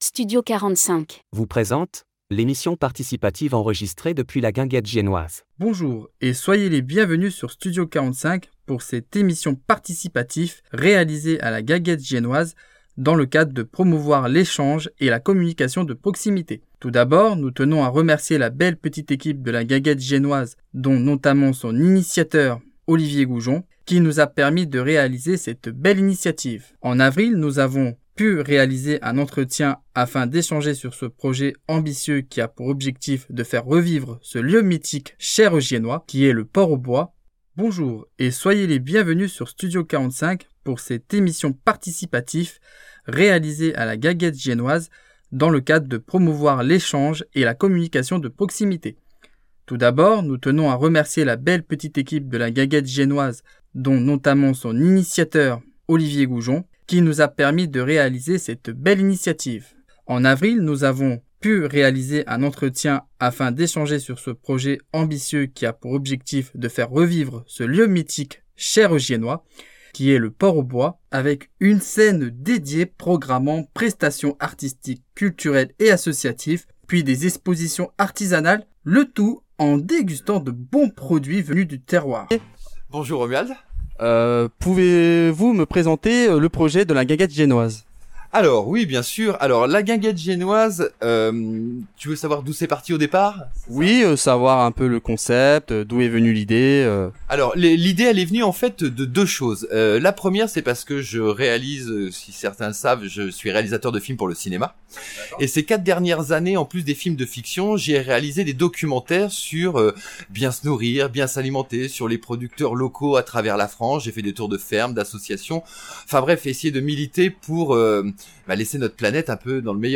Emission participative à la Guinguette Giennoise